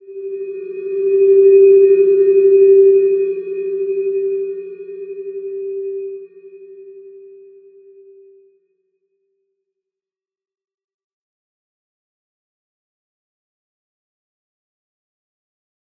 Slow-Distant-Chime-G4-mf.wav